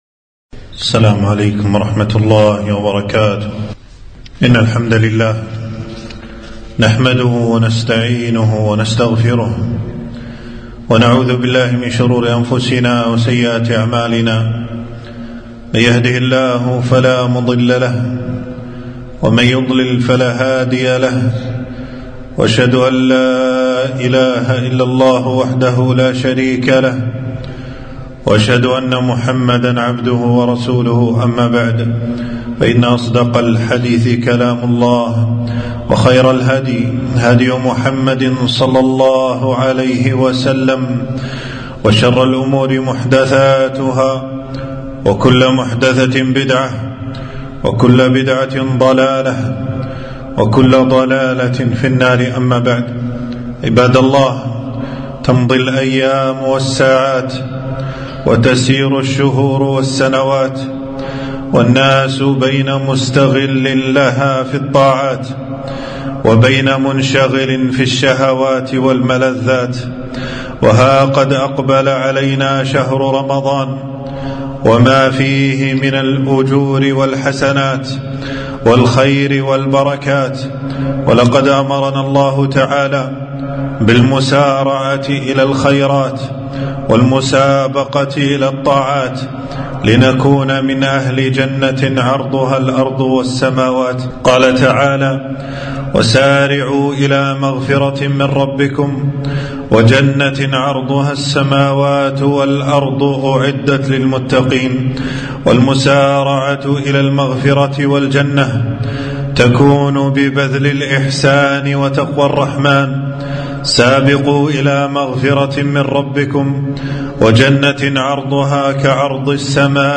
خطبة - أعدّوا لشهر رمضان العدة ففيه تفتح أبواب الجنة